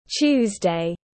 Thứ 3 tiếng anh gọi là tuesday, phiên âm tiếng anh đọc là /ˈtʃuːz.deɪ/
Tuesday /ˈtʃuːz.deɪ/